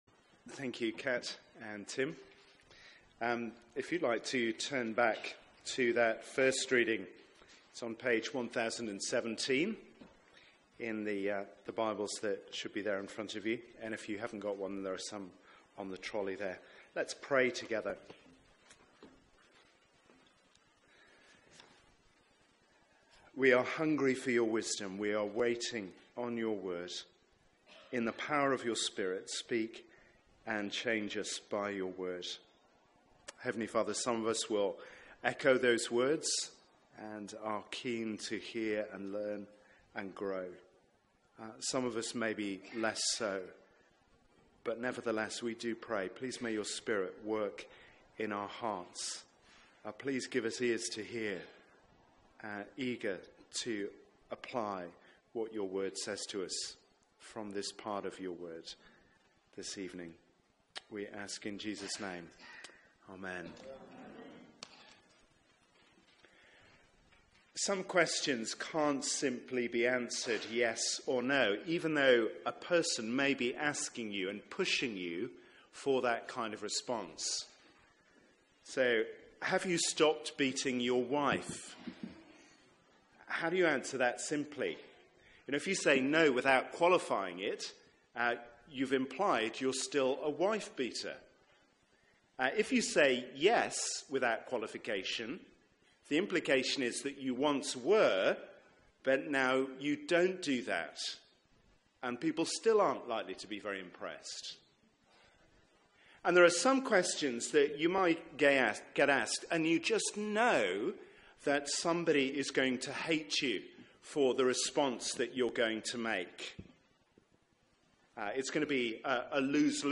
Media for 6:30pm Service on Sun 21st May 2017 18:30 Speaker
1 Peter 2:11-17 Series: Questioning the King Theme: What about Caesar? Sermon Search the media library There are recordings here going back several years.